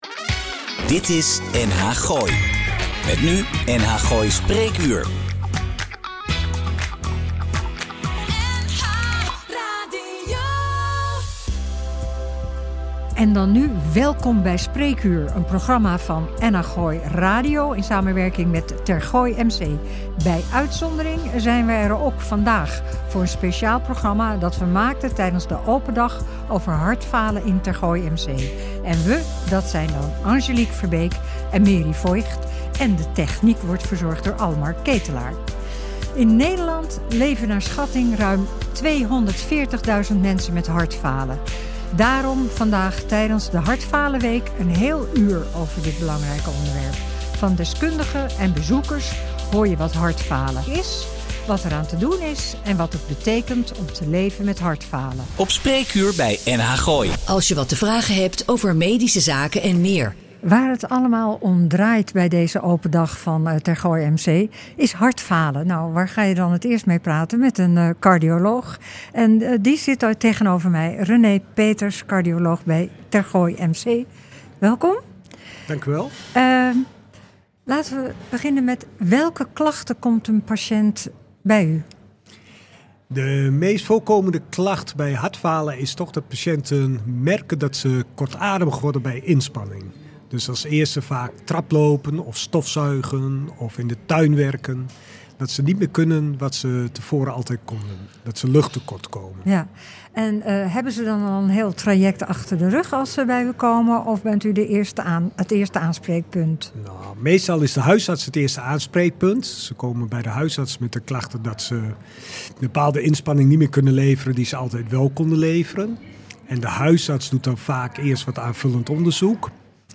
We praten hierover met deskundigen, werkzaam bij Tergooi MC en met twee hartpatiënten.